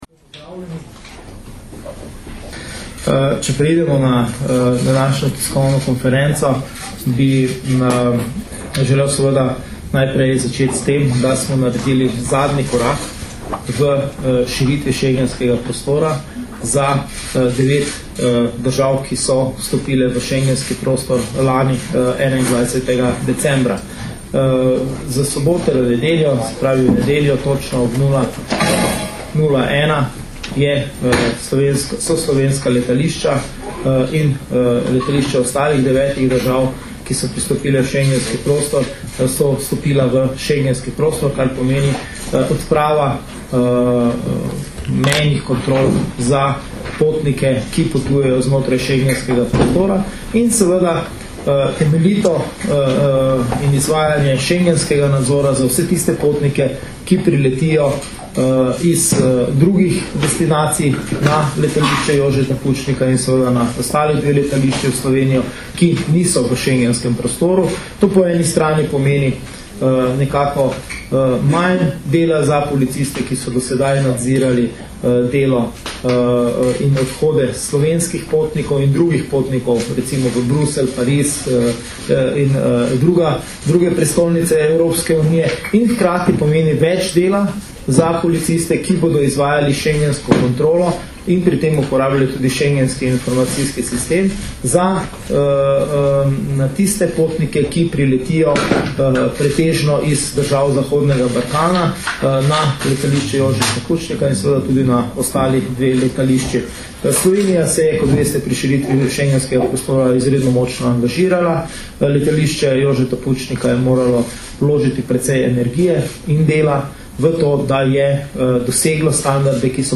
Predstavniki Ministrstva za notranje zadeve, Policije in Aerodroma Ljubljana so na današnji novinarski konferenci na letališču Jožeta Pučnika Ljubljana predstavili zgodovinski pomen dogodka za Slovenijo in druge schengenske države, še zlasti pri zagotavljanju varnosti v schengenskem prostoru.
Zvočni posnetek izjave Dragutina Mateta (mp3)